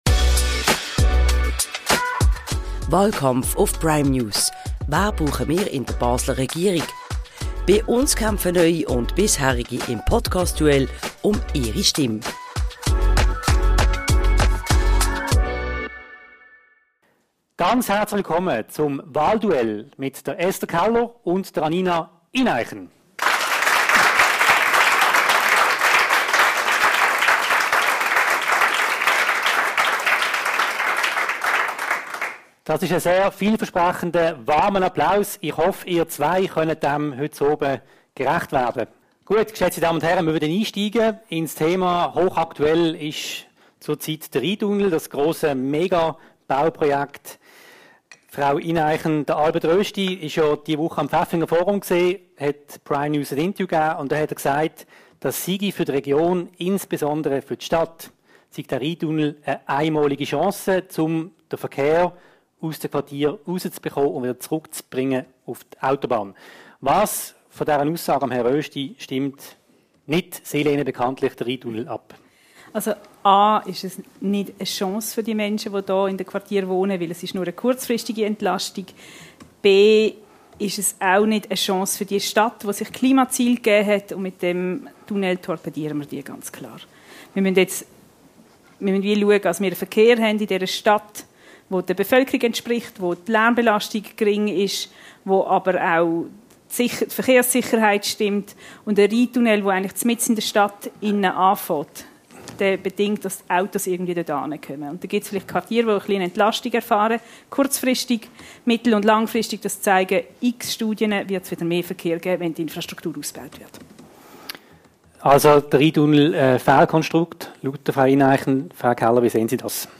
Die beiden Regierungskandidatinnen diskutieren vor Live-Publikum im Rialto über Rheintunnel, Wohnschutz und Stadtbegrünung.